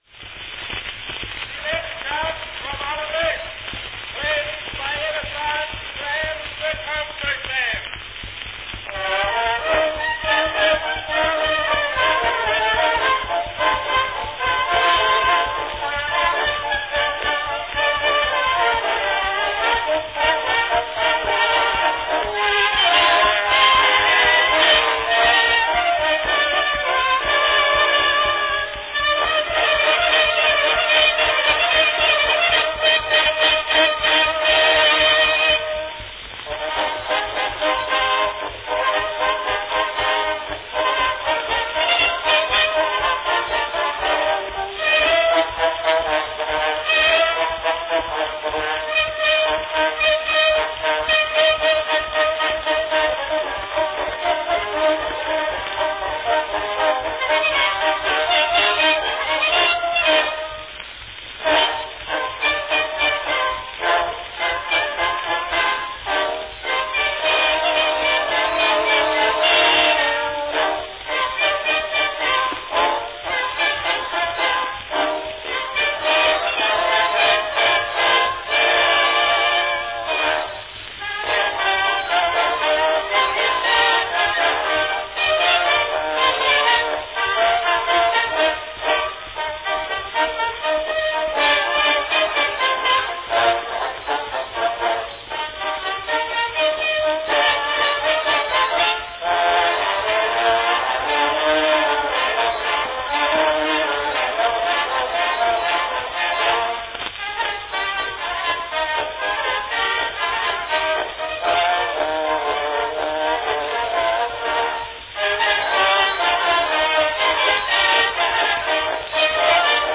Category Band
Performed by Edison Grand Concert Band
Announcement "Selections from Olivette, played by Edison's Grand Concert Band."
Enjoy this nearly forgotten but catchy music from Edmond Audran's 1879 three-act comic opera Les noces d'Olivette - or simply "Olivette" as it was known in the English adaptation.
This recording was possibly released in anticipation of the opera's early-1899 New York revival, and carries the brash sound (typical of this period) of a copy produced by pantograph from a 5" diameter master.